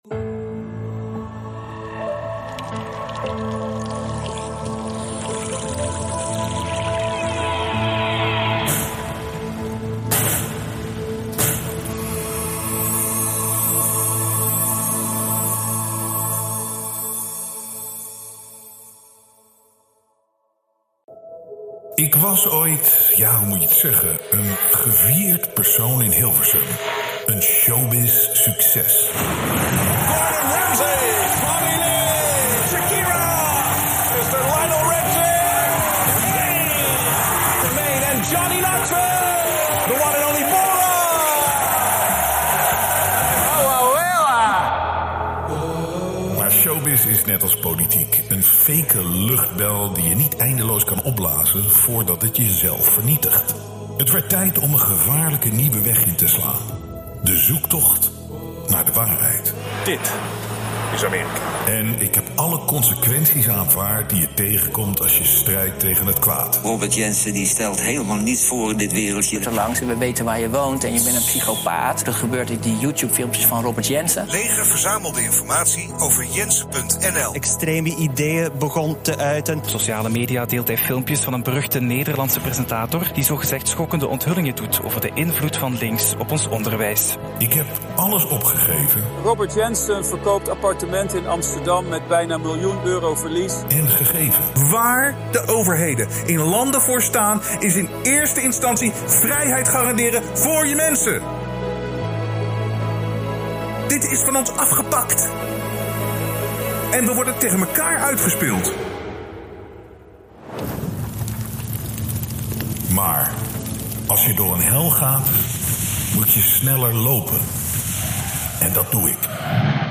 Dit is een experiment en dat is ook wel te te horen sorry daarvoor!